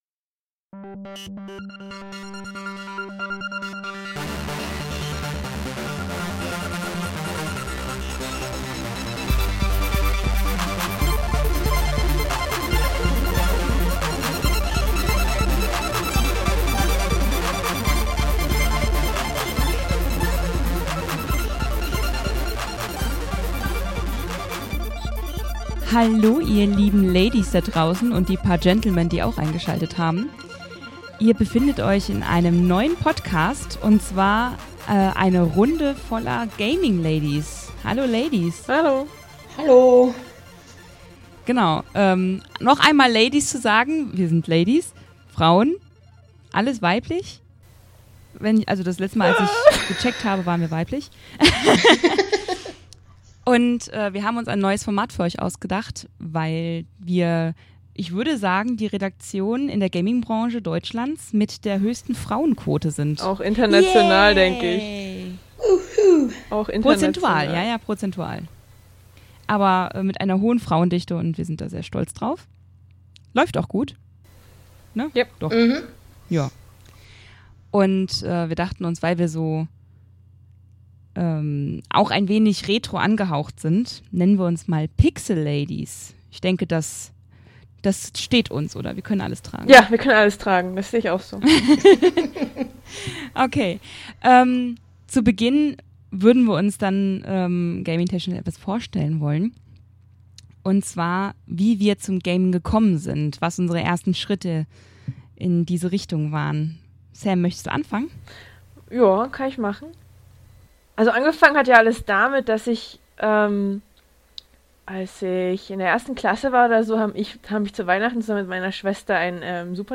Unser neuer Pixelfrauen-Podcast wird ab sofort monatlich von den Damen unserer Redaktion geschmissen.